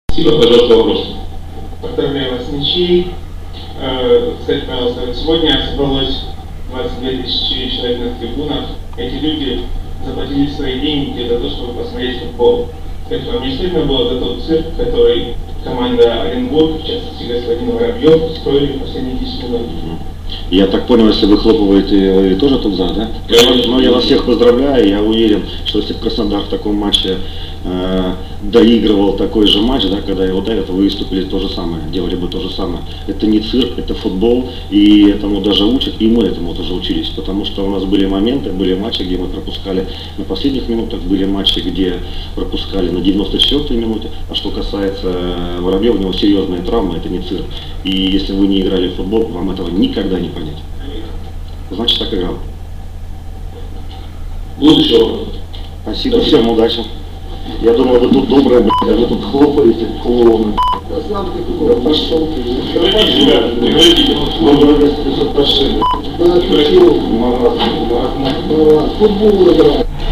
Накануне состоялся футбольный матч между «Оренбургом» и «Краснодаром». После матча, ознаменовавшегося ничьей, состоялось пресс-конференция.